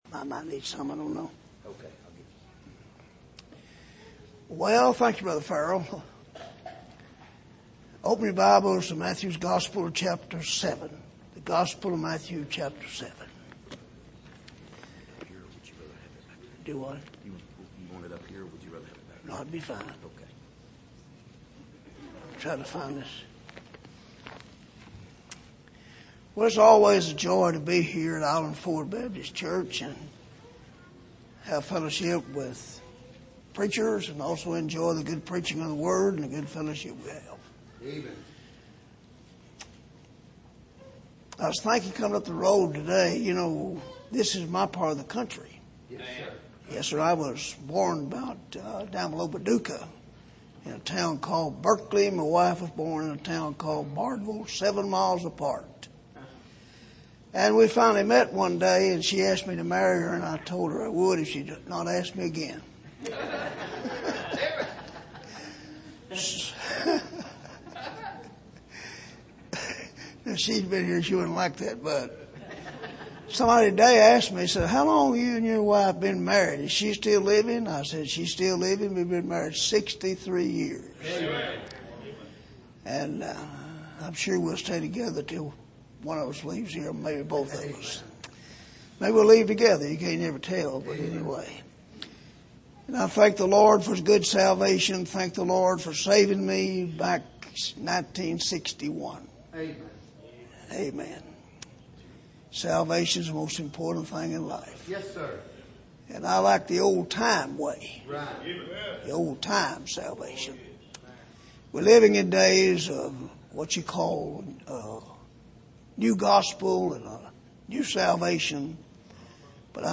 2021 Missions Conference Passage: Matthew 7:24-28 Service: Missions Conference The Sayings of Jesus « The Path of the Upright